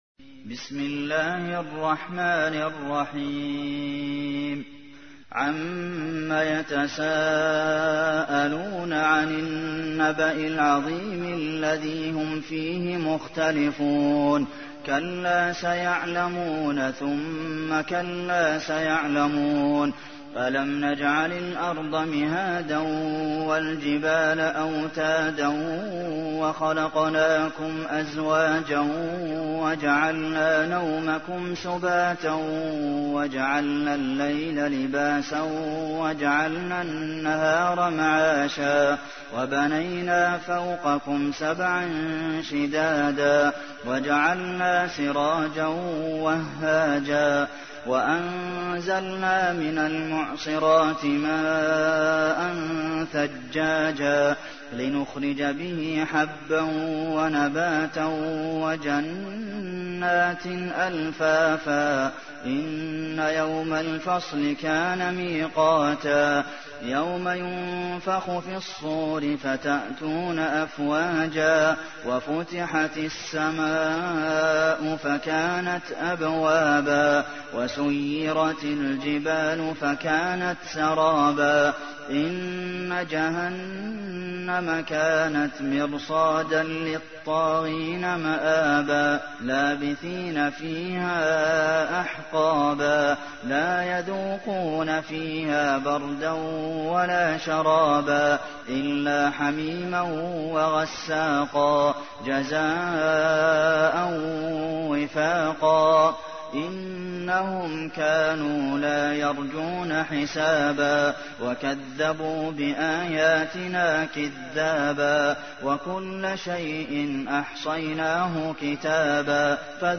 تحميل : 78. سورة النبأ / القارئ عبد المحسن قاسم / القرآن الكريم / موقع يا حسين